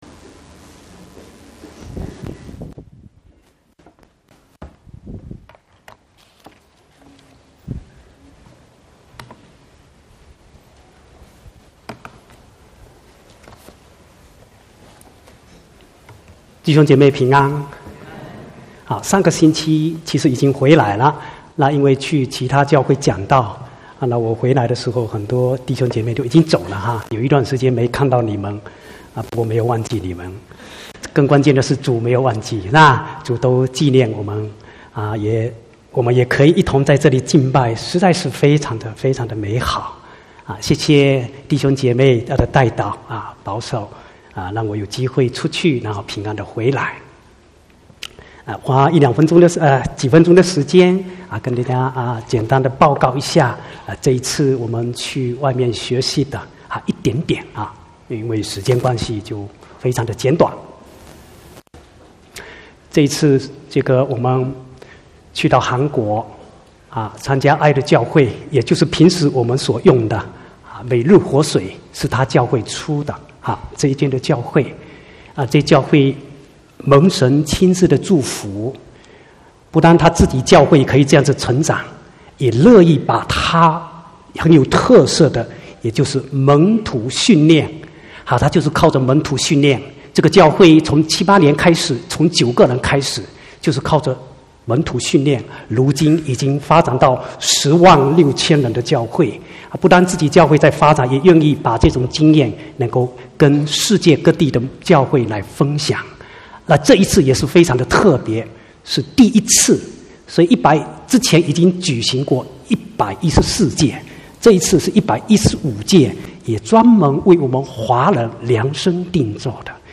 24/11/2019 國語堂講道